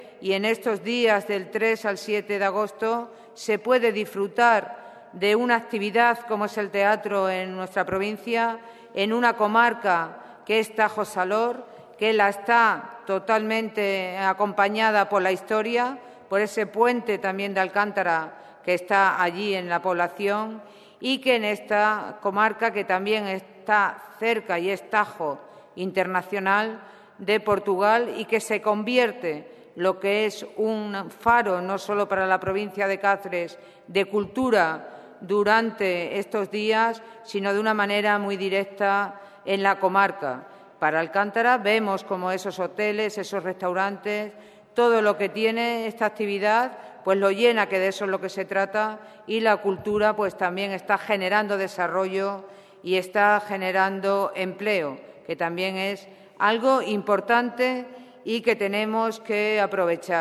Charo Cordero, presidenta de la Diputación, destaca en la presentación del Festival de Teatro Clasico de Alcántara, el impulso que supone para el desarrollo de las comarcas.
CORTES DE VOZ